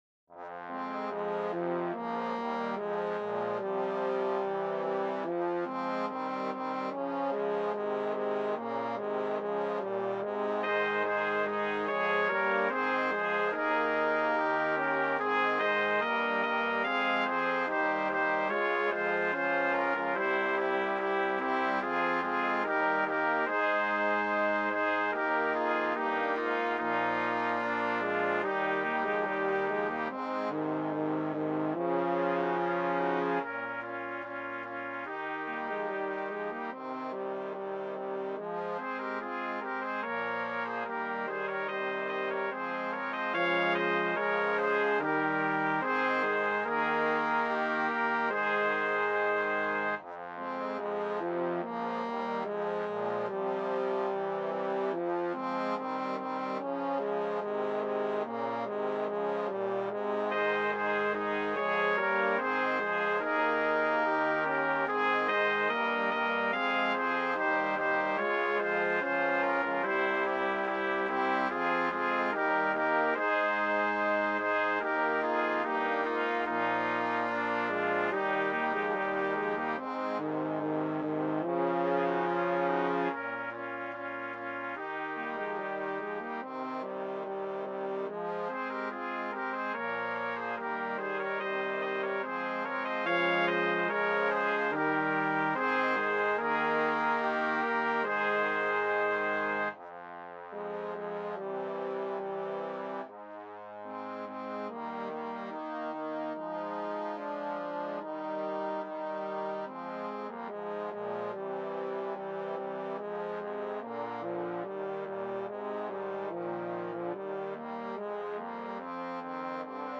BRASS QUINTET
STANDARD BRASS QUINTET